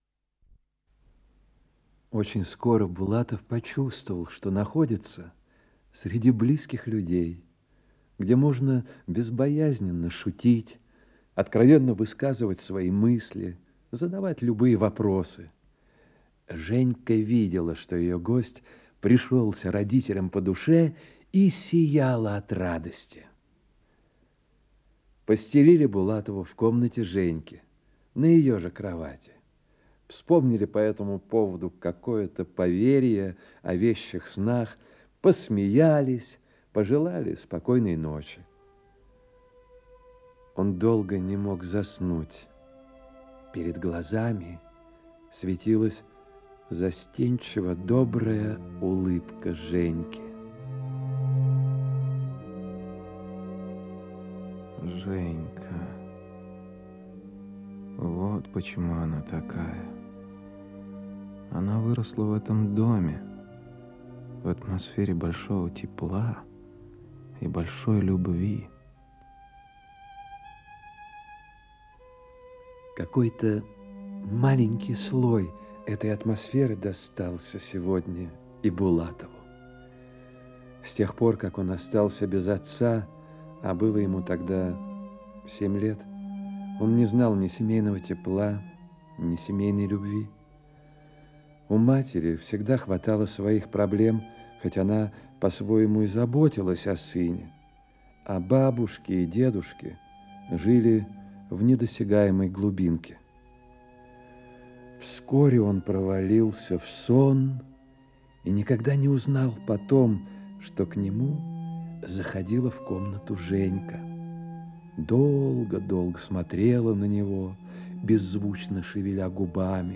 Радиоспектакль